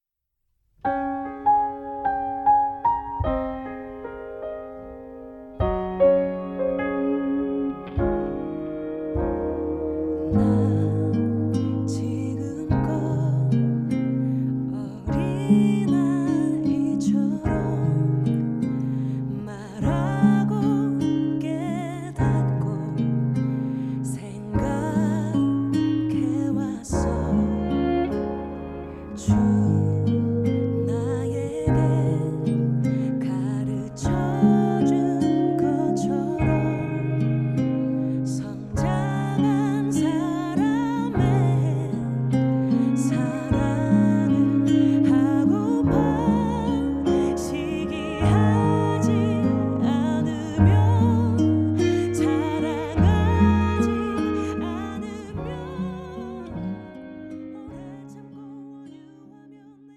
음정 -1키
장르 가요 구분
가사 목소리 10프로 포함된 음원입니다